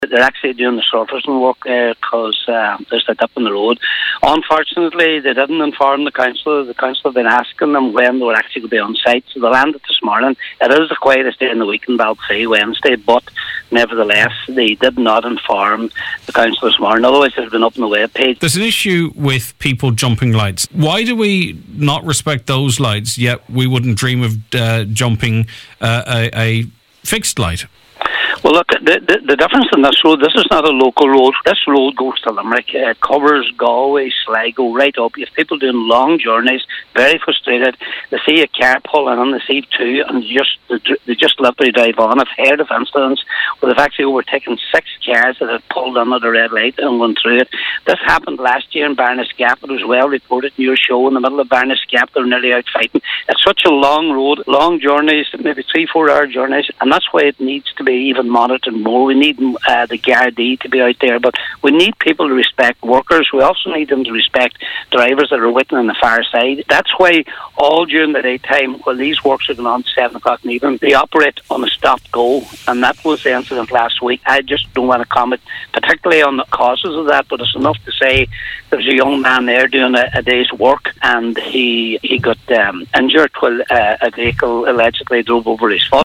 Speaking on the Nine Til Noon Show, local Cllr Patrick McGowan said this is ongoing work on a bridge, and the council was unaware that the contractors would be back on site today.